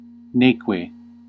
Ääntäminen
IPA : /nɒt/